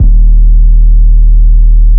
808 WestonRoad.aif